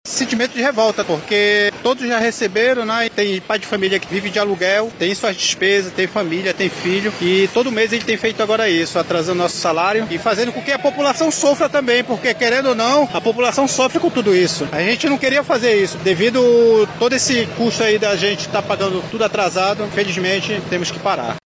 Também na zona Oeste da cidade, o trabalhador do Transporte Coletivo